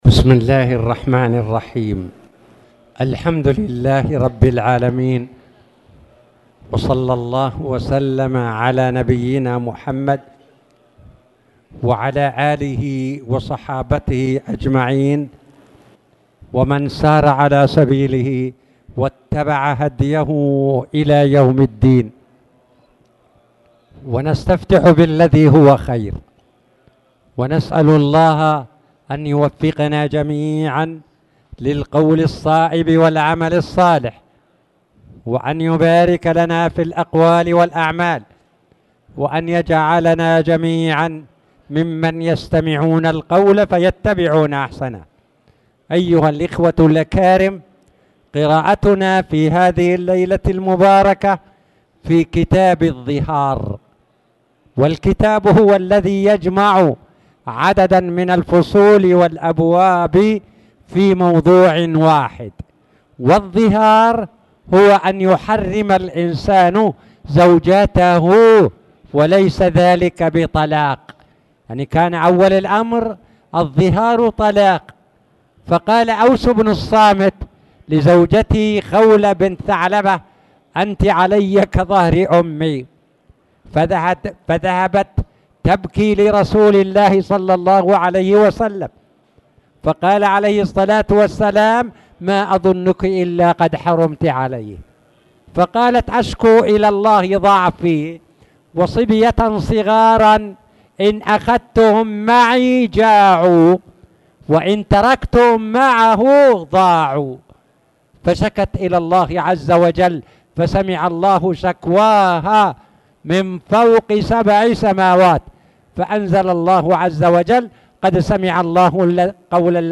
تاريخ النشر ١٨ شعبان ١٤٣٨ هـ المكان: المسجد الحرام الشيخ